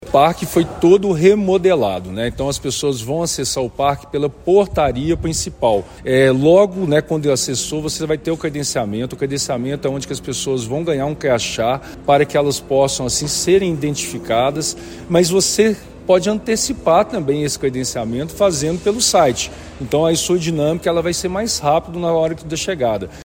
Além disso mais de 80 expositores participaram da feira, índice que será superado, como destaca o secretario municipal de Esporte, Lazer e Turismo, Helton Simão: